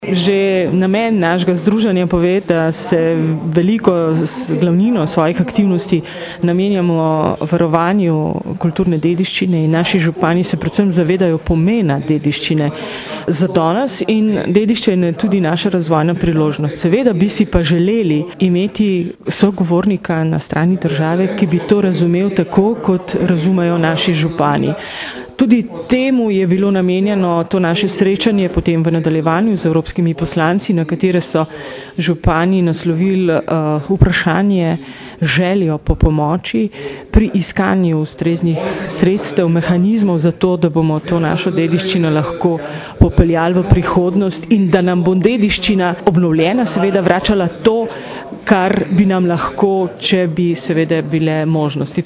izjava_mag.borutsajoviczupanobcinetrzic.mp3 (1,1MB)